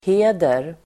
Uttal: [h'e:der]